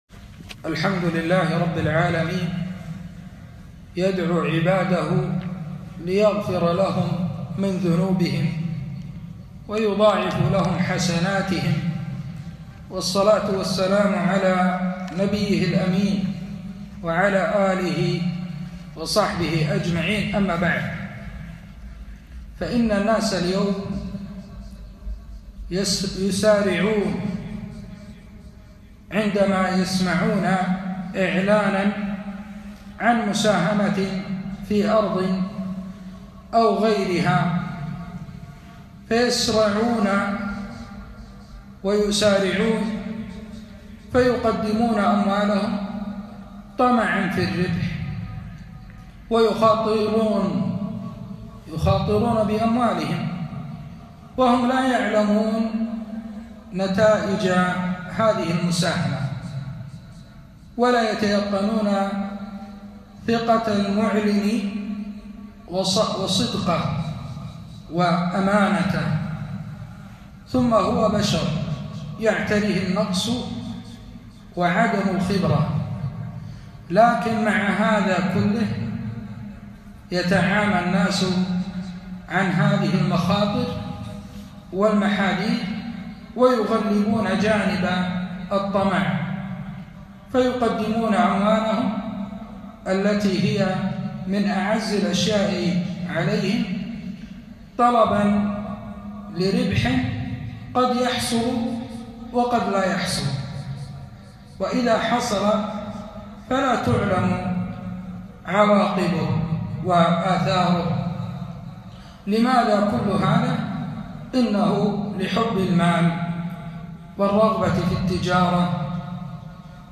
كلمة - التجارة الرابحة